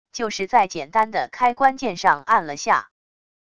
就是在简单的开关键上按了下wav音频